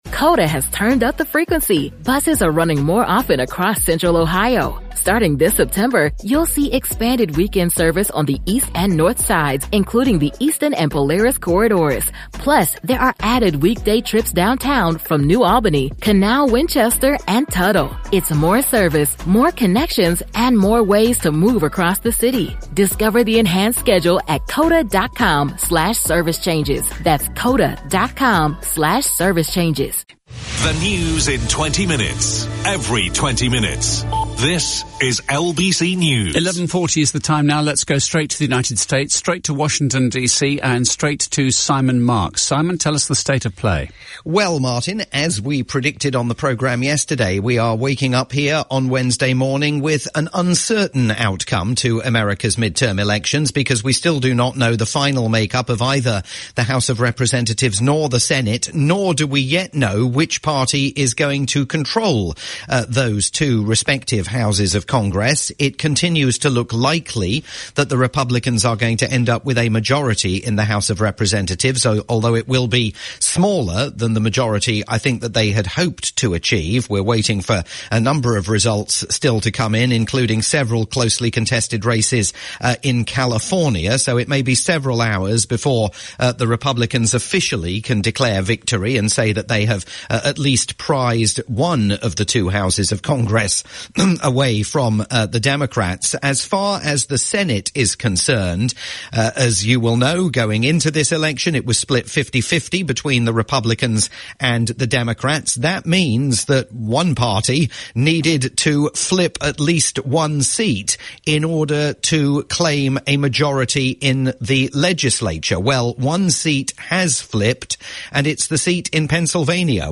live update on the latest in the midterms